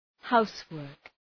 Προφορά
{‘haʋswɜ:rk}